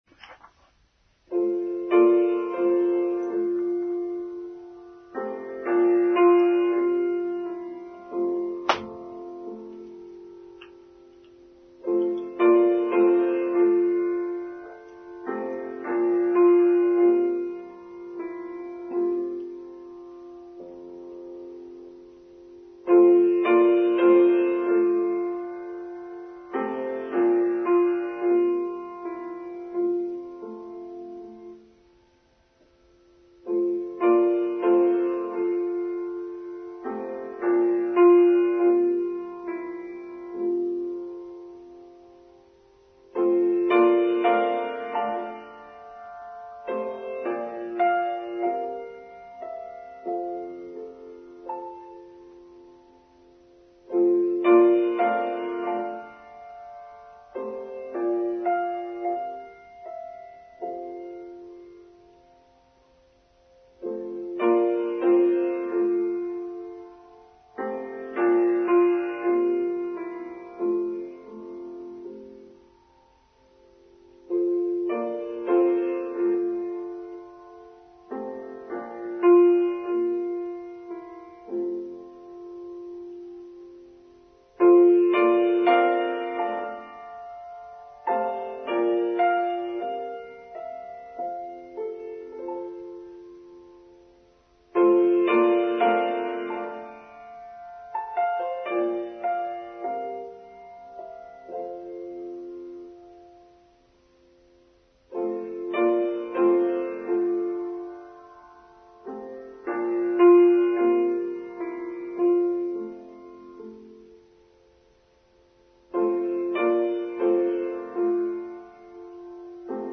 The Wind and the Spirit: Online service for Sunday 28th May 2023